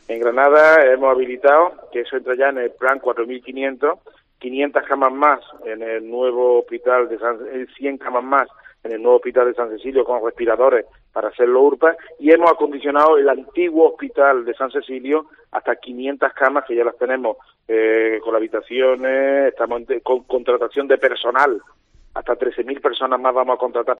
ENTREVISTA COPE ANDALUCÍA